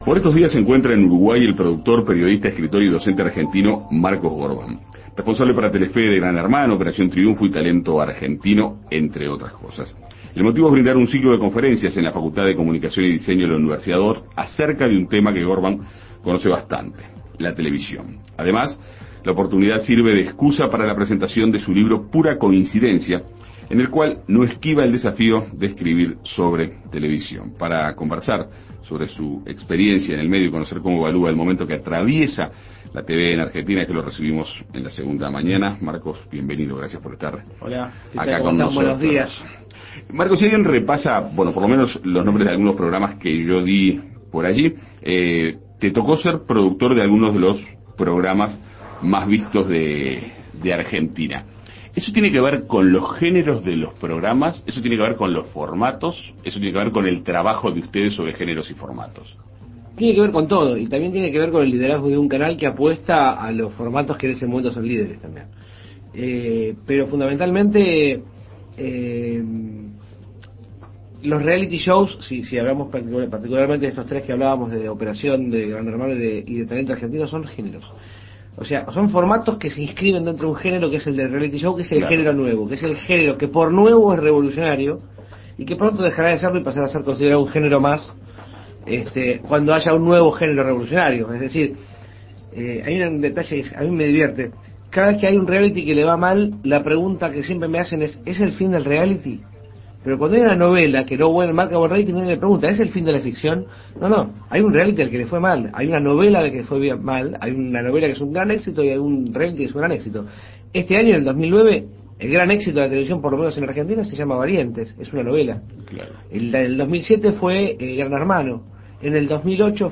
En Perspectiva Segunda Mañana dialogó con el multifacético empresario.